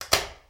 Switch (6).wav